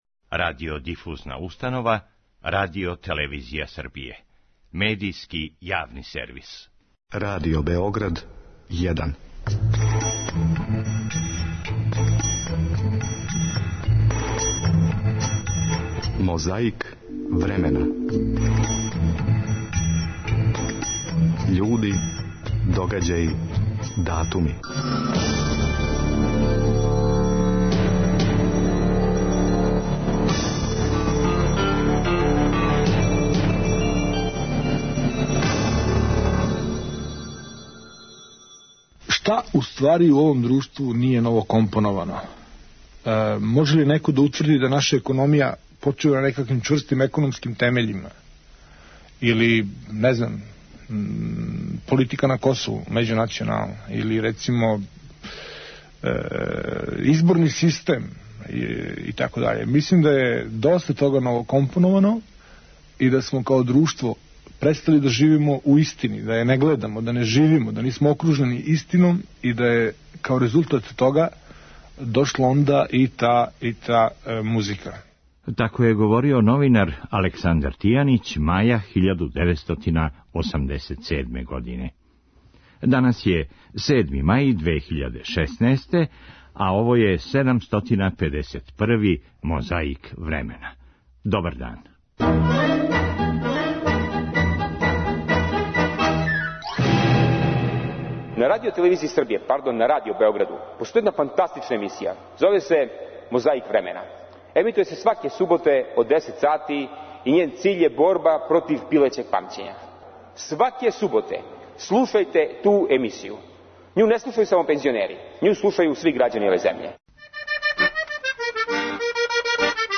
Поводом 40 година победе над фашизмом, таква парада одржана је у Београду 9. маја 1985. године.
Писац Данило Киш дао је интервју за емисију „Радиоскоп" Радио Беогарда, маја 1980. године.